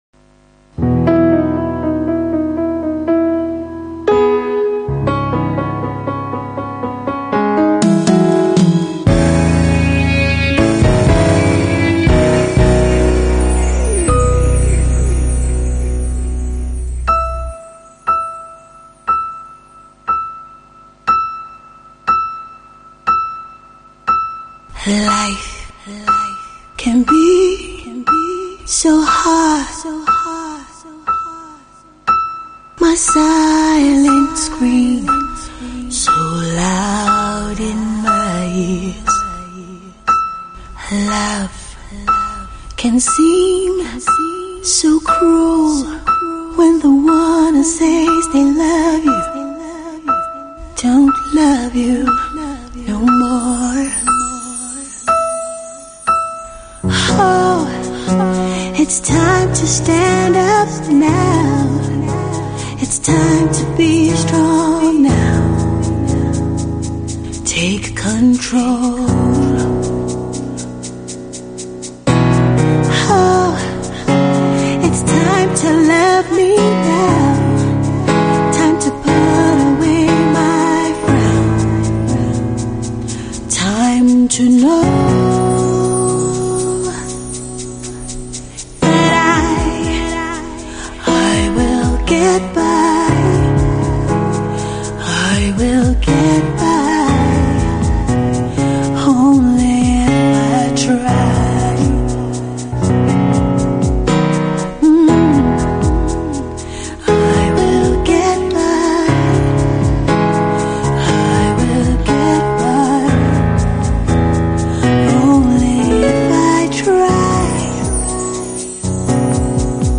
The motivating song